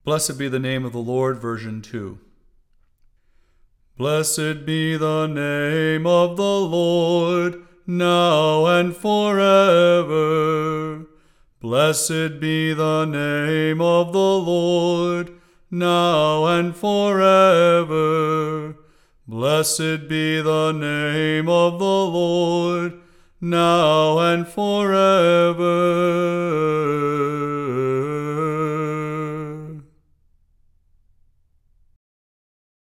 St. John Chrysostom Melkite Catholic Church » Vespers at Lake House Aug. 14, 2010